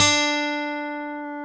Index of /90_sSampleCDs/Roland L-CD701/GTR_Steel String/GTR_ 6 String
GTR 6-STR30X.wav